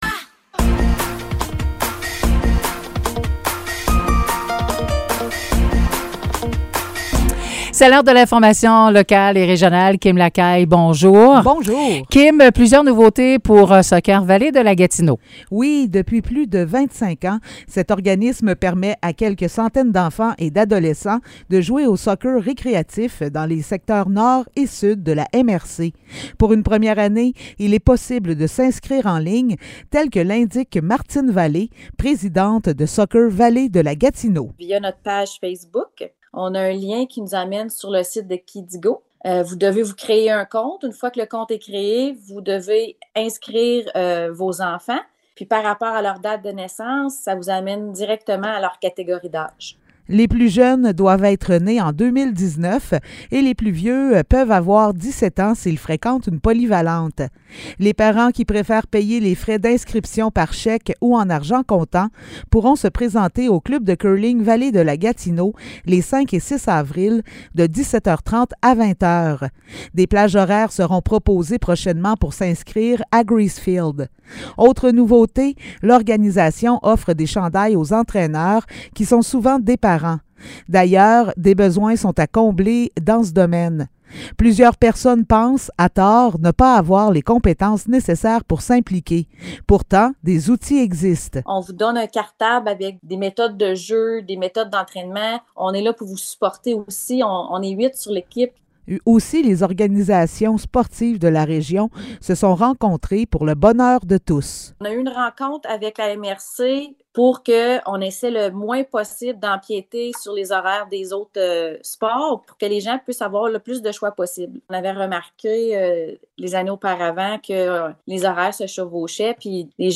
Nouvelles locales - 24 mars 2023 - 10 h